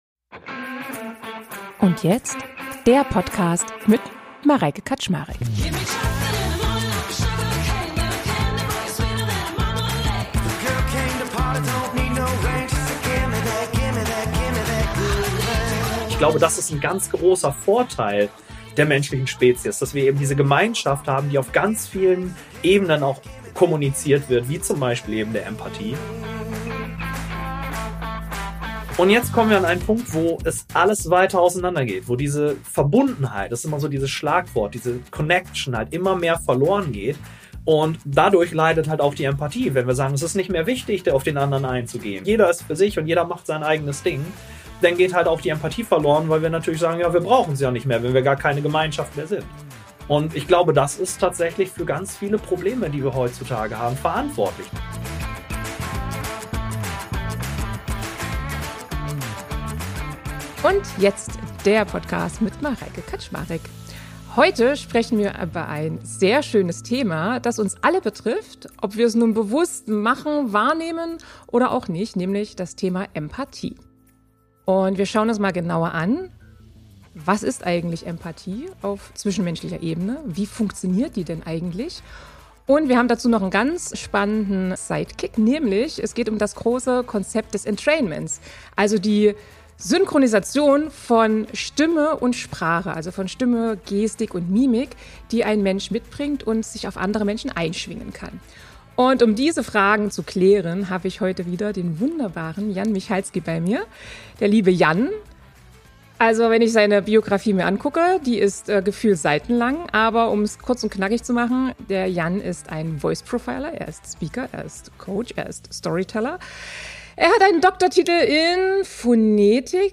Interview | Warum fühlen wir uns mit manchen Menschen sofort verbunden – und mit anderen nicht?